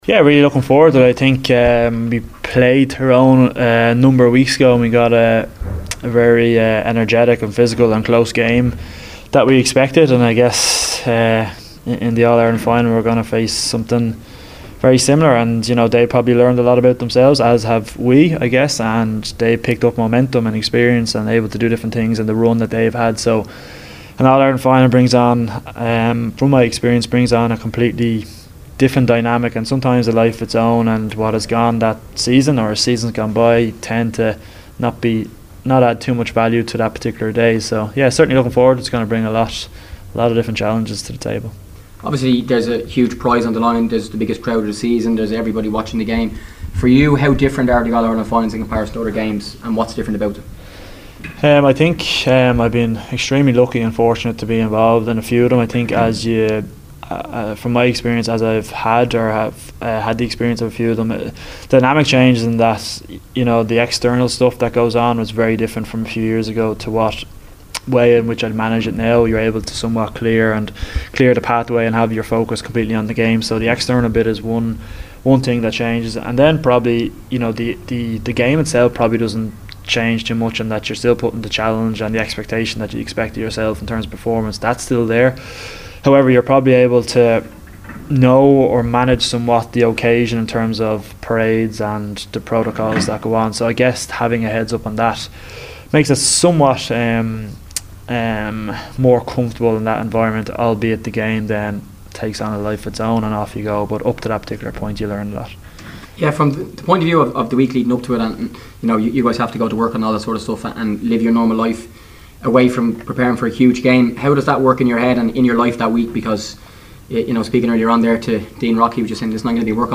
We’ll hear from Dean Rock shortly, but first here’s Johnny Cooper on the current Tyrone challenge…